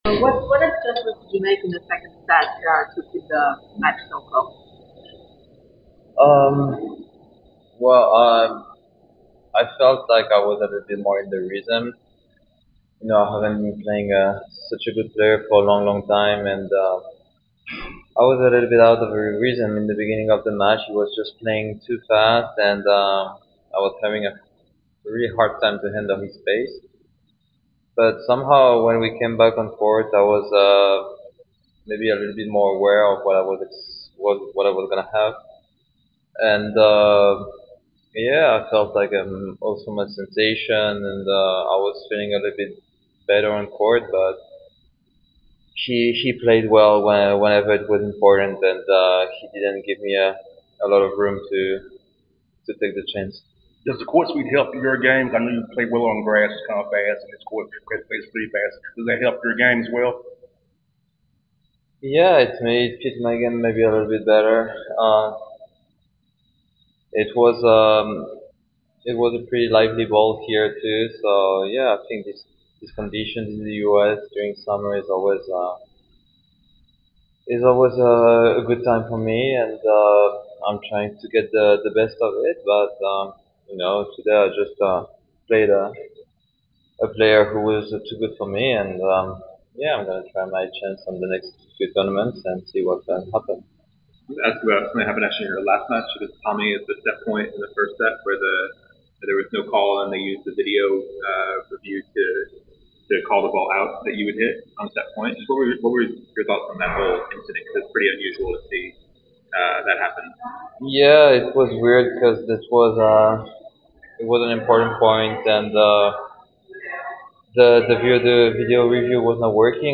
Adrian Mannarino post-match interview after losing to Jannik Sinner 4-6, 6-7 in the Round of 16 of the Cincinnati Open.